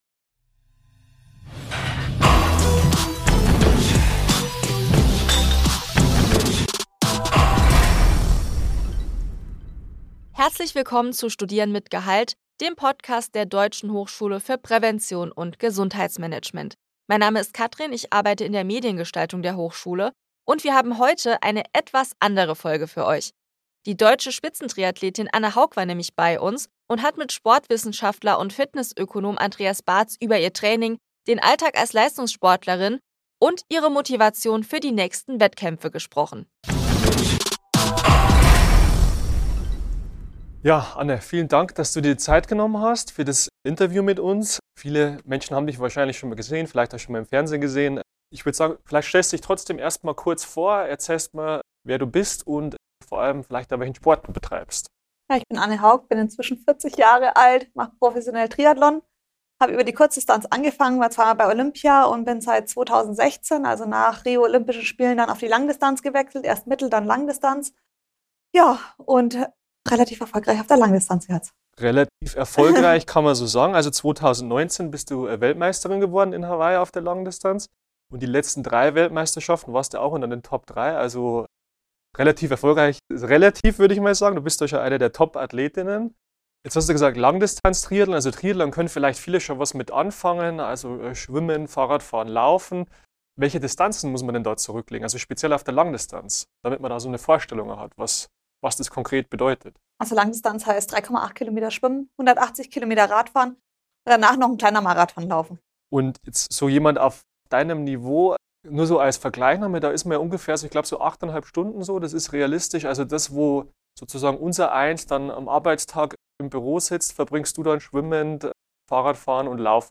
Triathlon-Athletin Anne Haug im Interview: Training, Alltag und Motivation ~ Studieren mit Gehalt Podcast